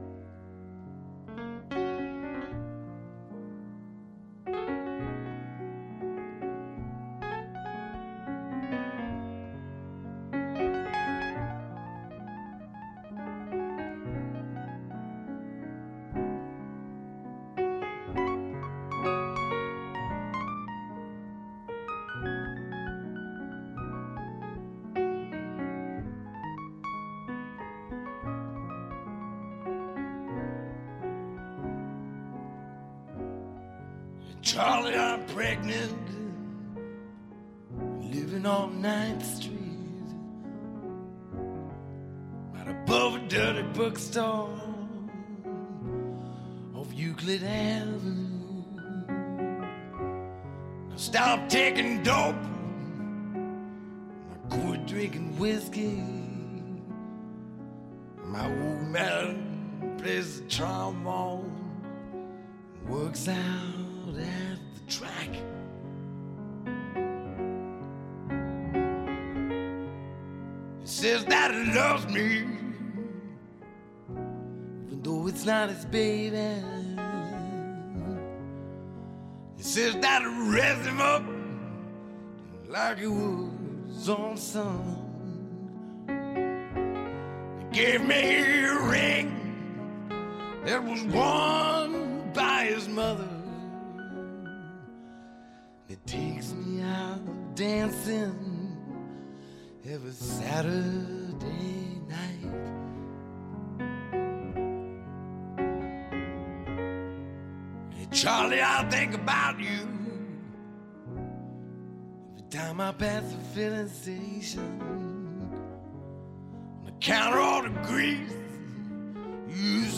σε ένα χαλαρό “απογευματινό καφέ”.
ΜΟΥΣΙΚΗ